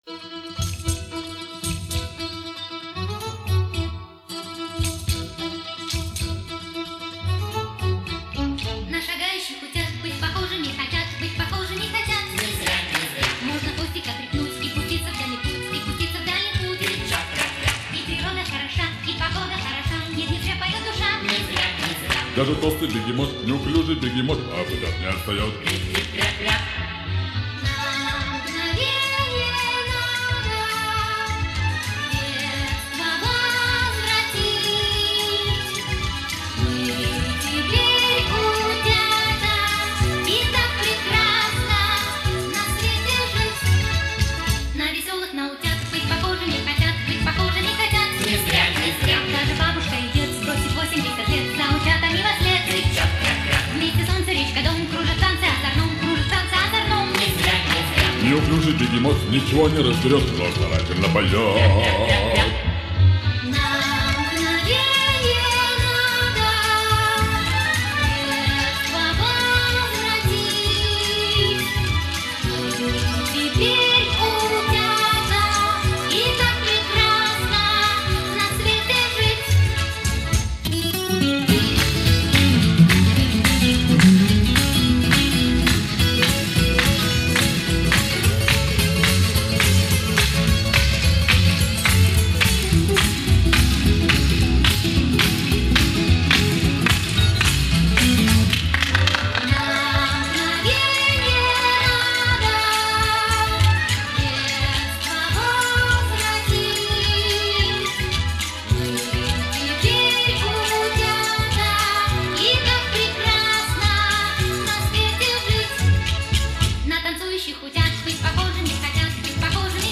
Веселая детская песенка